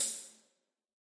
tamborine.ogg